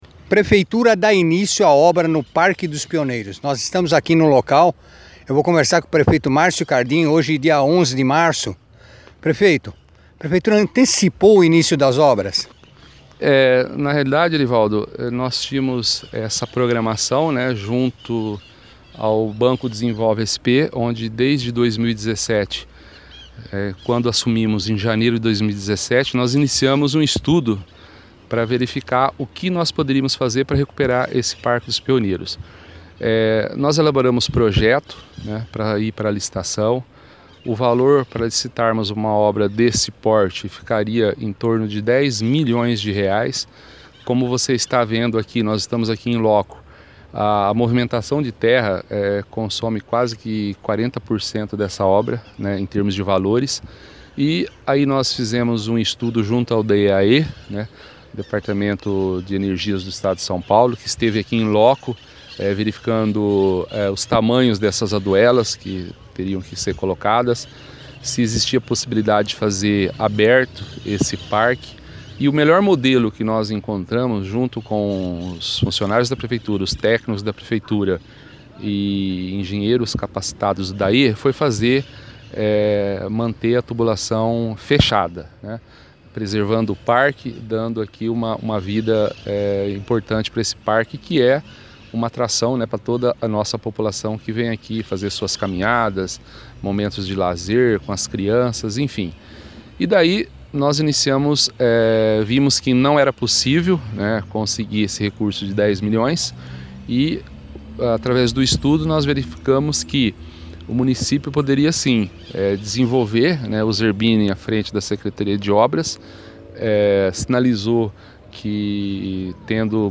entrevista com o prefeito Márcio Cardim